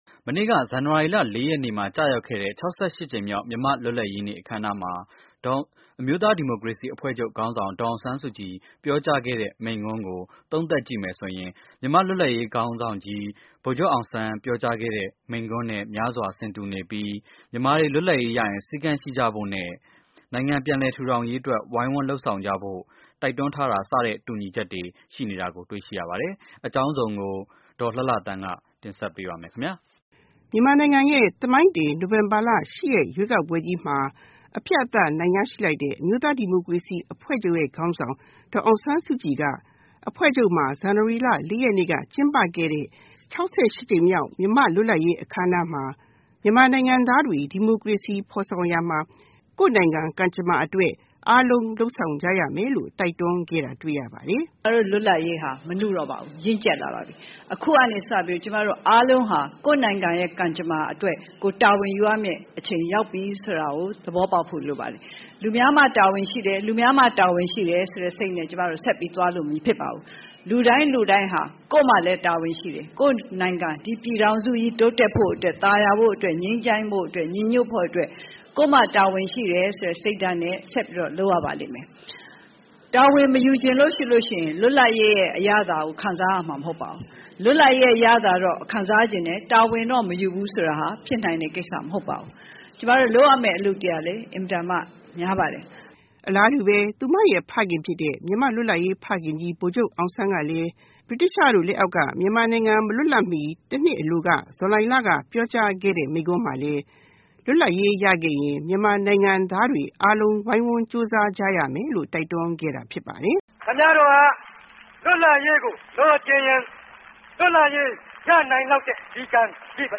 DASSK's speech